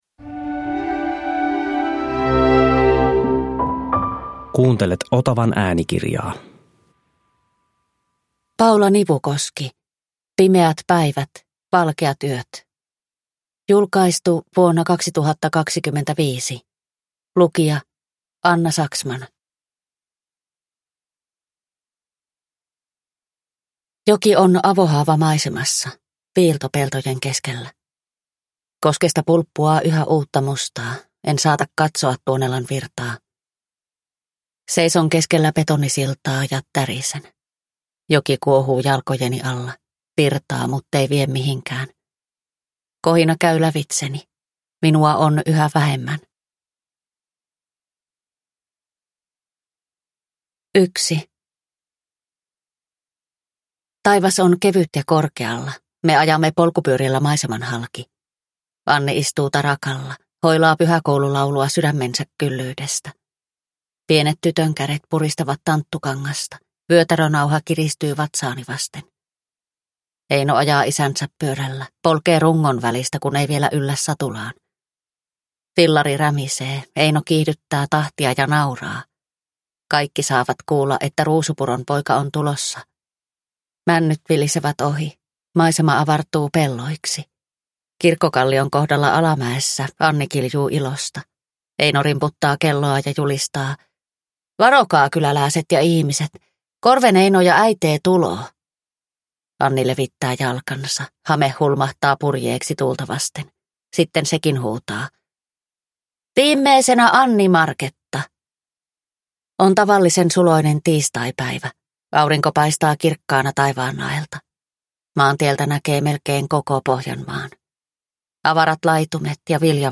Pimeät päivät, valkeat yöt – Ljudbok